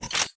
Reloading_finish0005.ogg